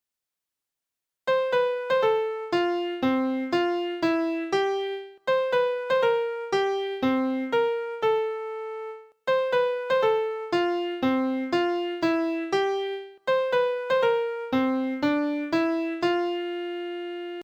This song is sung in two parts.